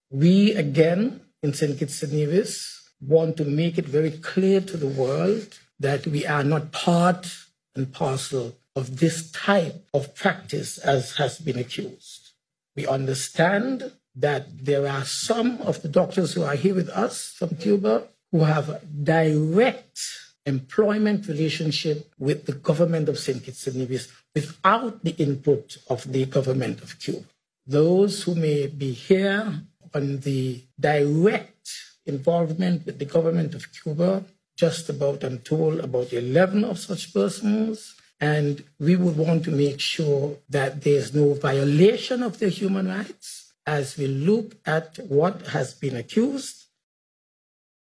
Minister Douglas made this declaration: